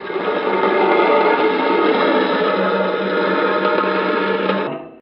vault sounds
vaultScrape.ogg